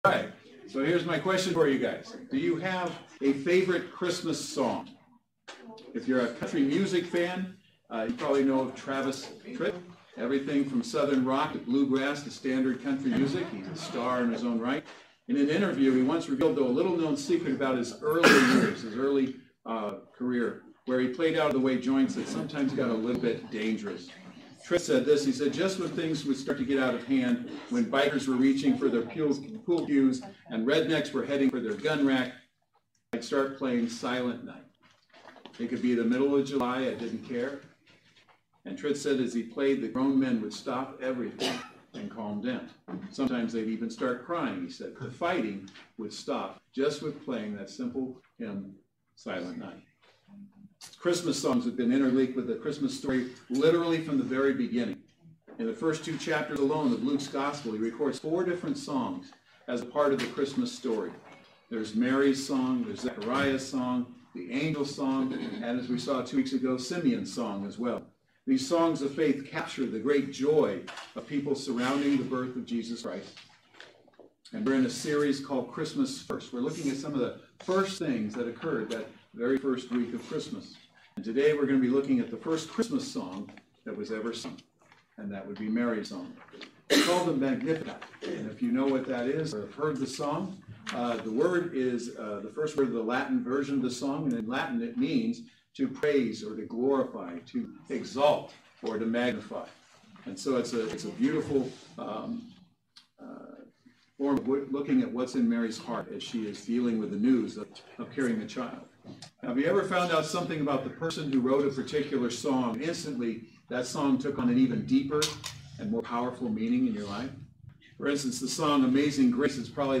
Christmas Firsts Service Type: Saturday Worship Service Speaker